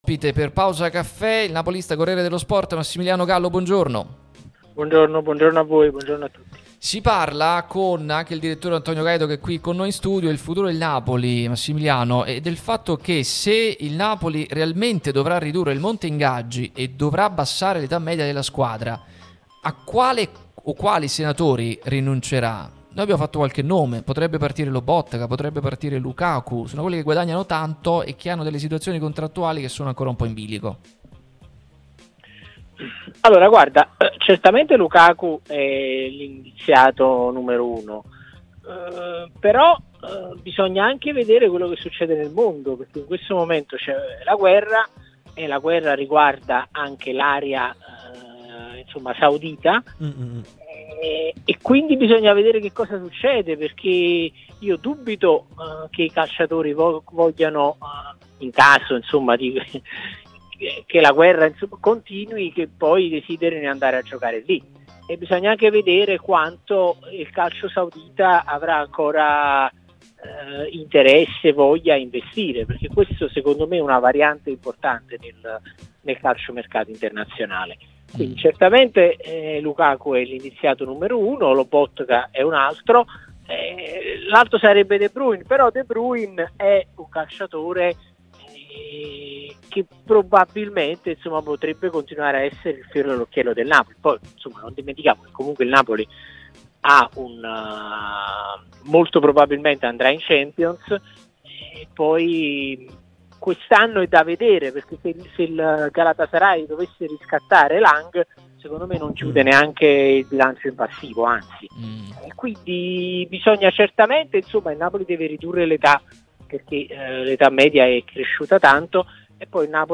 Il giornalista